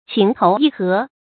注音：ㄑㄧㄥˊ ㄊㄡˊ ㄧˋ ㄏㄜˊ
情投意合的讀法